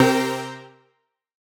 Index of /musicradar/future-rave-samples/Poly Chord Hits/Straight
FR_SOBX[hit]-C.wav